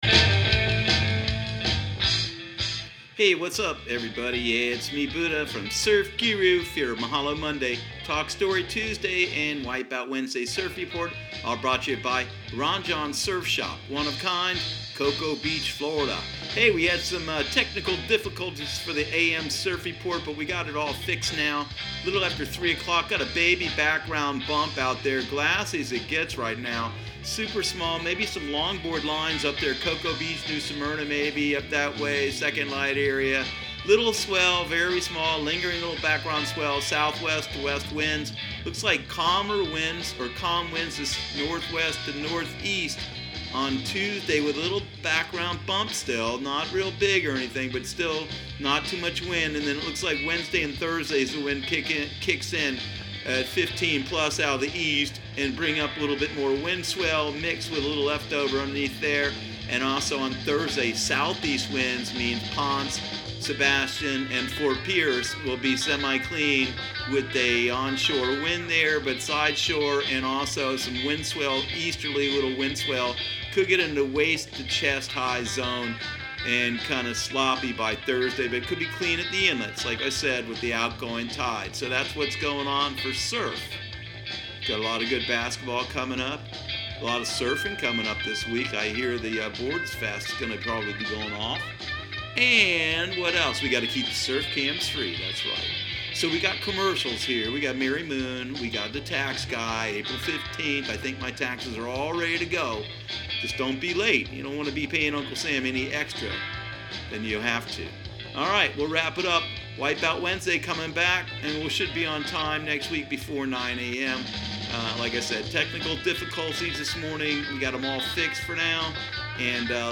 Surf Guru Surf Report and Forecast 03/11/2019 Audio surf report and surf forecast on March 11 for Central Florida and the Southeast.